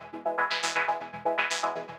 SaS_MovingPad04_120-E.wav